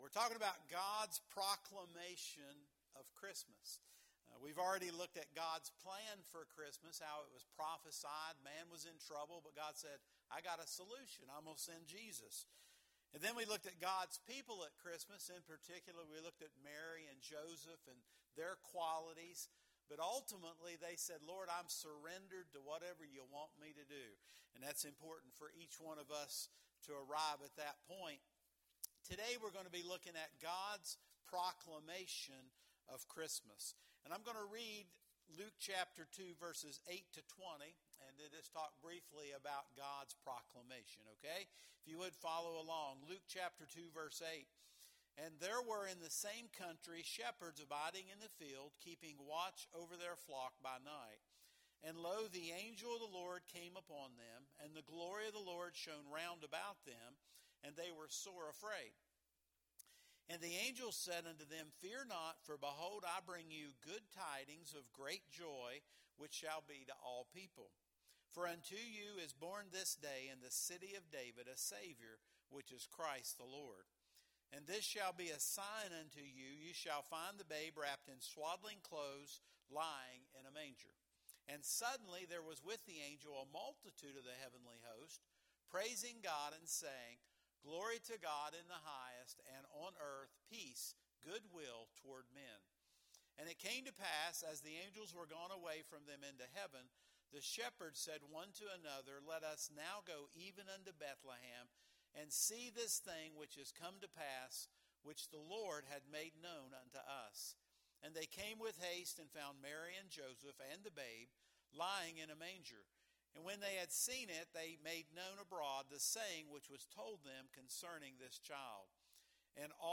Sermons | Oak Mound Evangelical Church